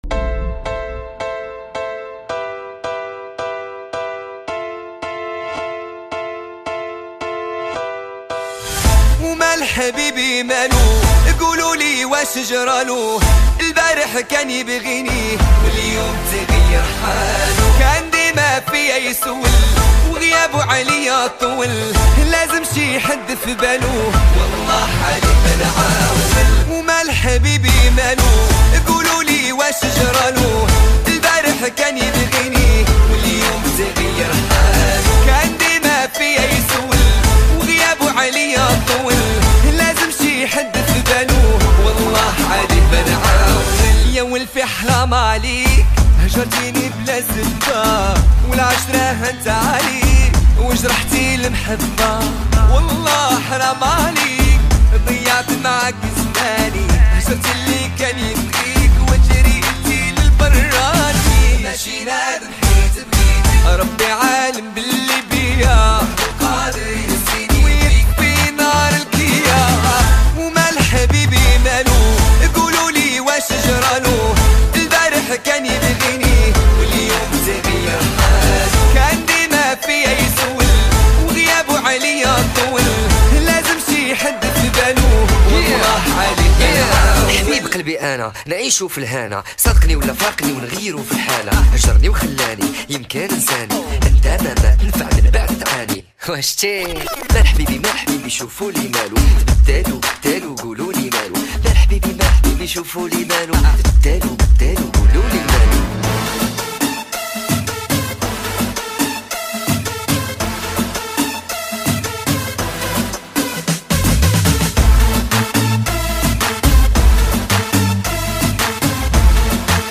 دانلود ریمیکس عربی شاد